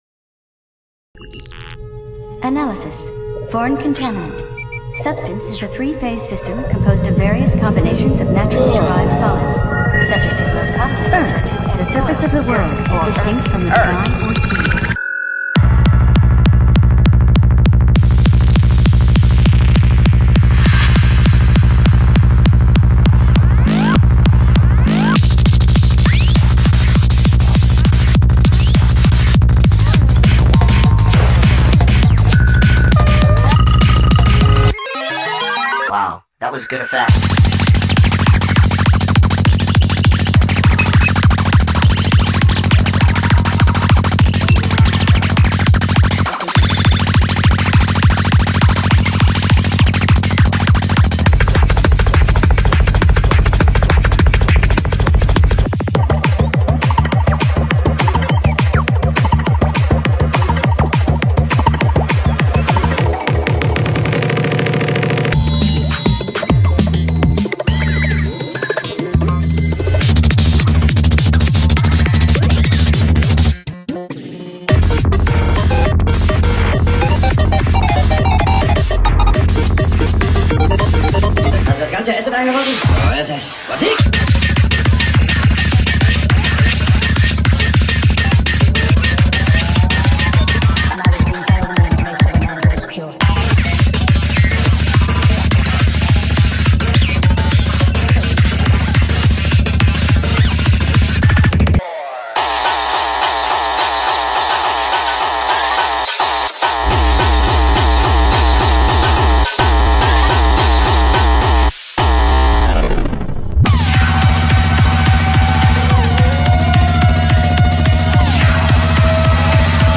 BPM200-220